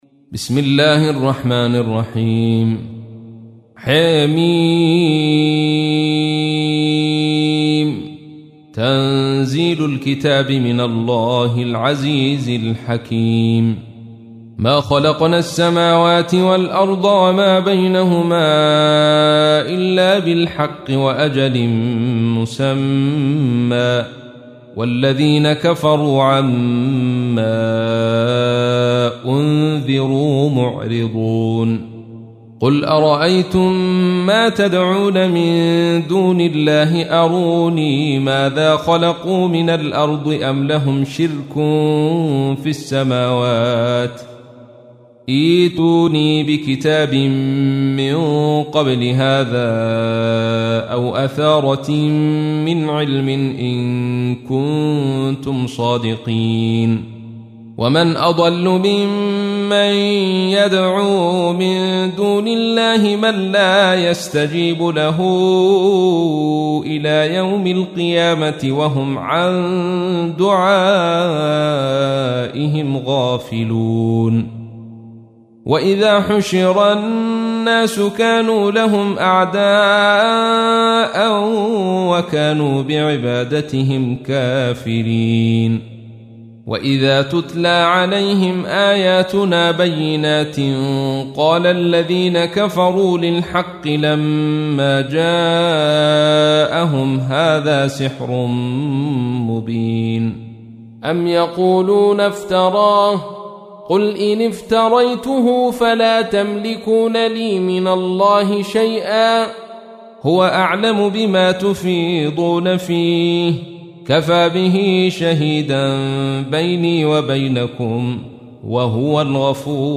تحميل : 46. سورة الأحقاف / القارئ عبد الرشيد صوفي / القرآن الكريم / موقع يا حسين